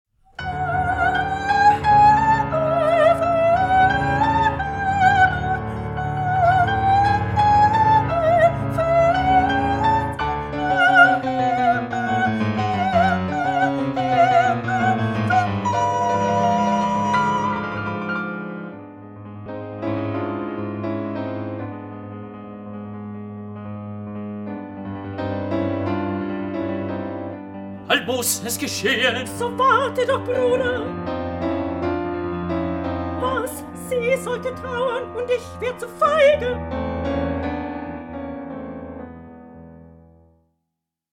Wind (Instrumental)
12_wind_instrumental.mp3